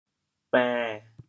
国际音标 [pε̃]
bên7.mp3